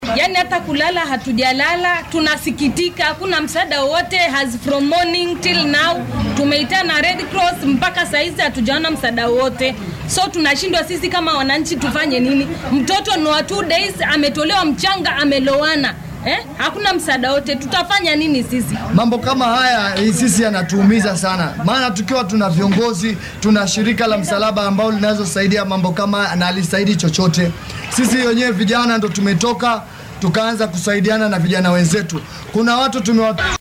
Qaar ka mid ah dadka deegaanka Voi ayaa ka hadlay saameynta ka soo gaartay daadadka.